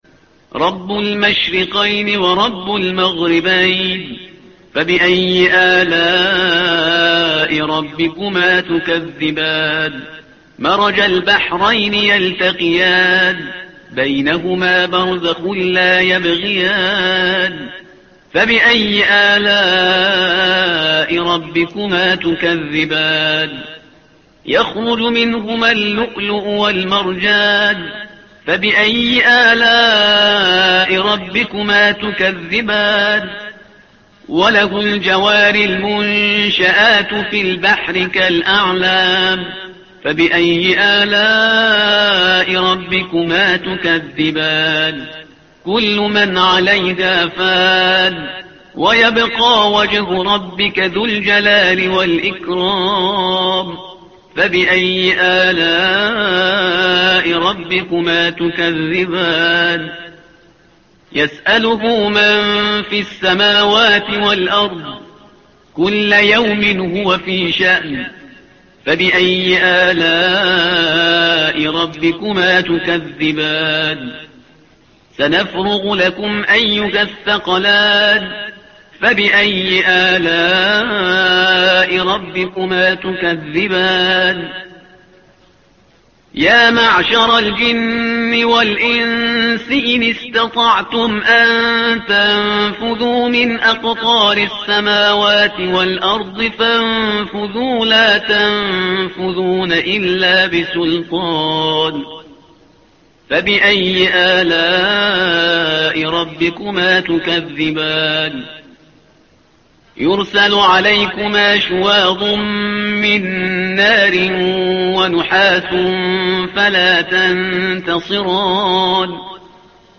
تحميل : الصفحة رقم 532 / القارئ شهريار برهيزكار / القرآن الكريم / موقع يا حسين